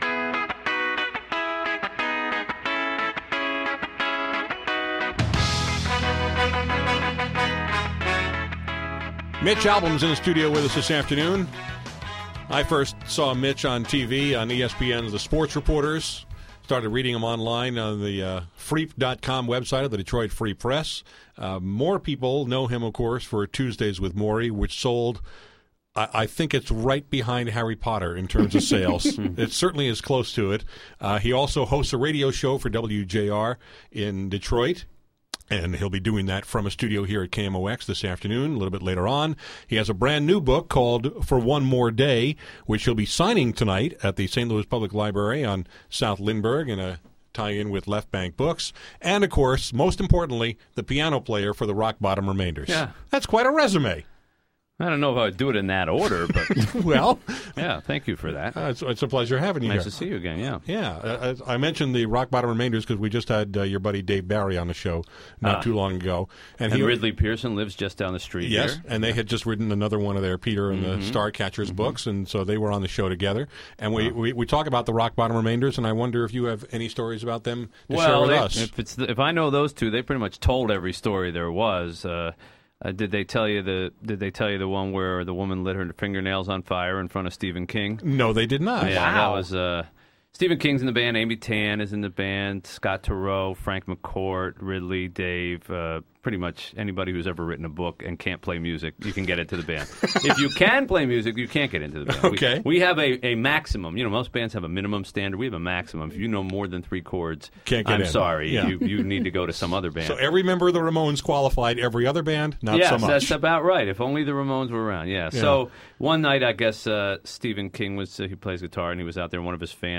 Mitch Albom was in my studio this afternoon to talk about his new book, “For One More Day.”